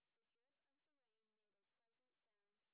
sp12_white_snr20.wav